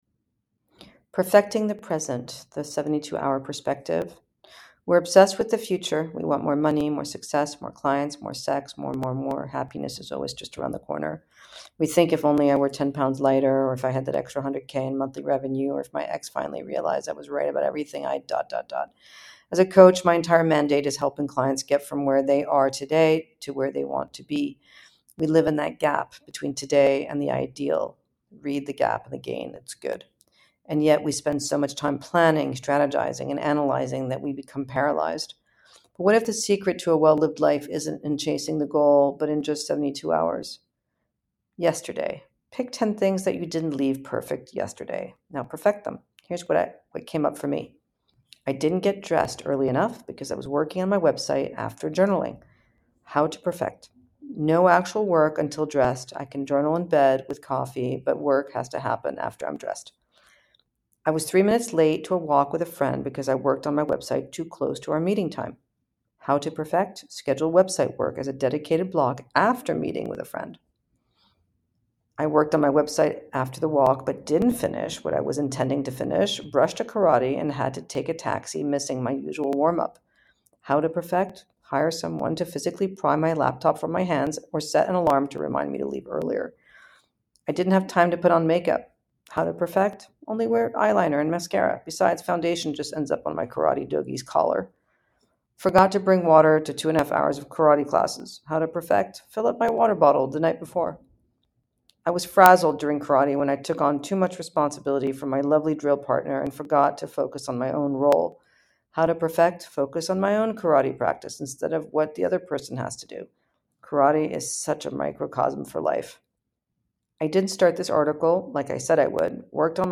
A narrated essay from The Pressures of Privilege.